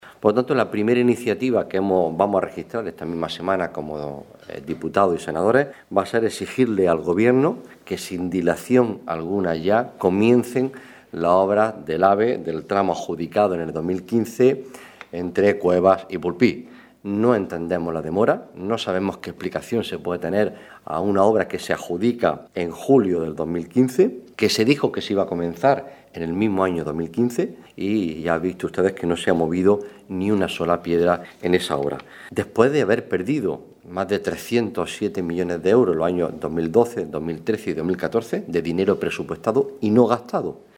Rueda de prensa que ha ofrecido el Grupo Parlamentario del PSOE de Almería para presentar las primeras iniciativas que registrará en las Cortes Generales en esta Legislatura